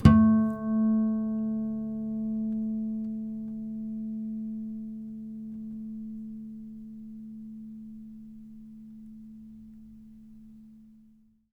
harmonic-04.wav